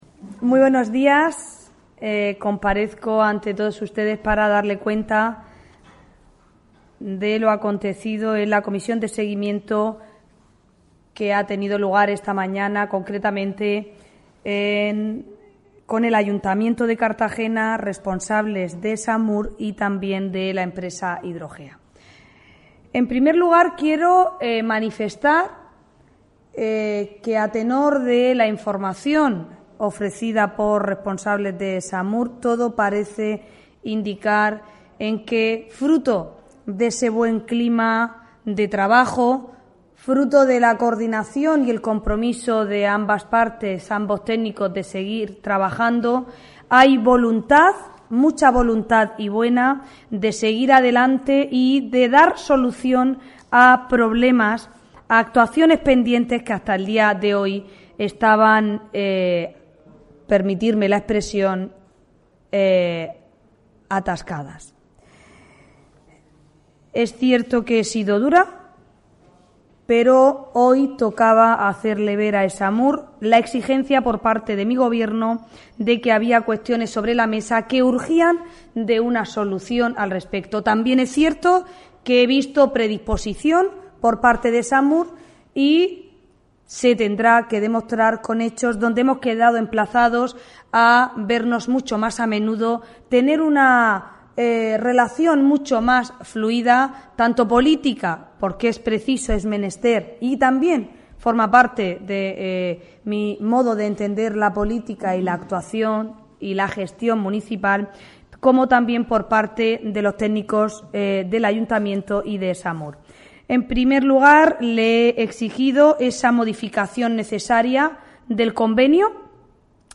Audio: Declaraciones alcaldesa tras la reuni�n de la Comisi�n de Seguimiento del Convenio entre el Ayuntamiento de Cartagena y ESAMUR (MP3 - 3,44 MB)